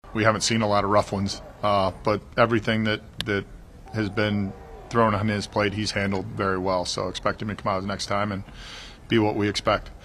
Manager Derek Shelton says he expects Skenes to bounce back in his next start.